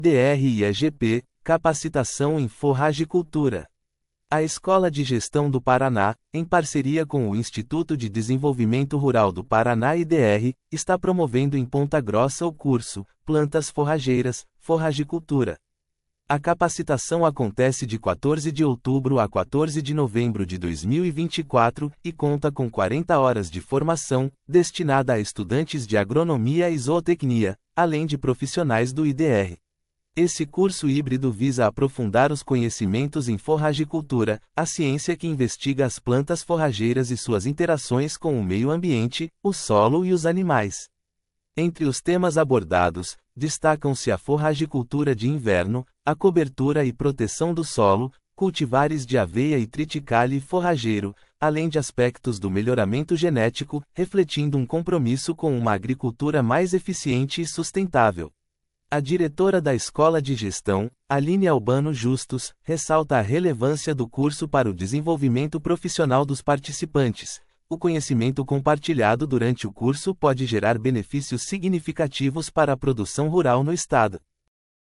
audionoticia_forragicultura.mp3